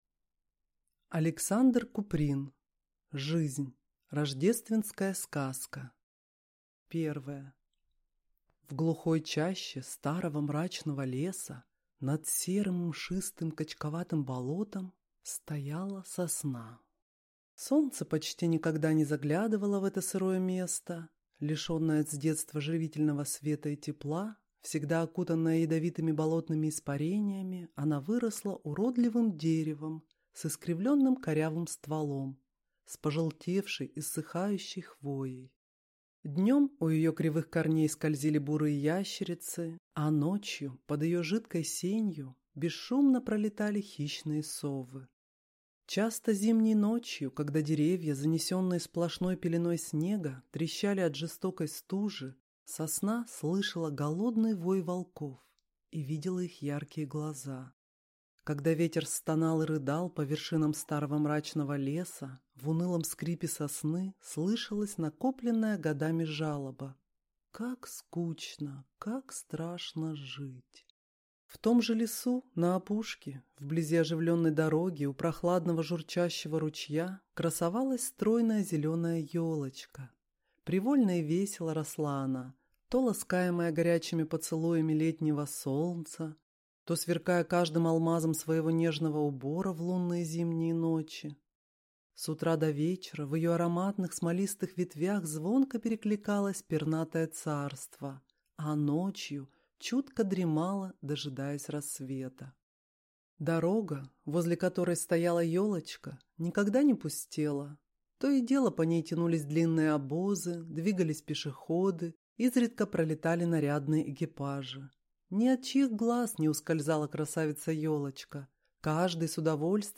Аудиокнига Жизнь | Библиотека аудиокниг